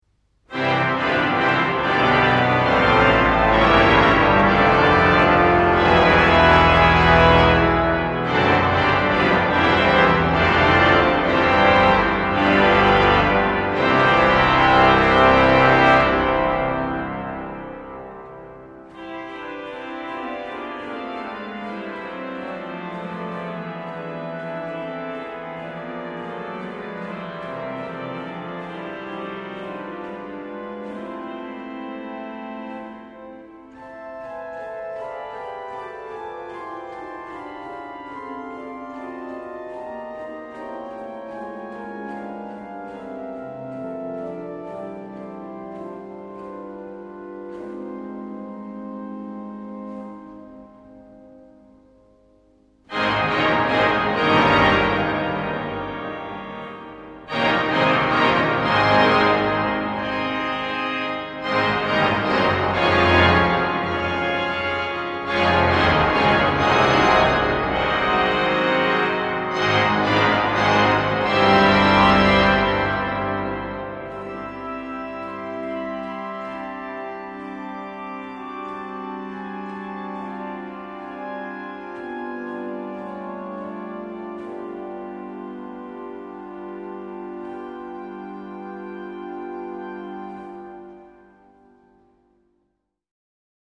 1854-1857 organiste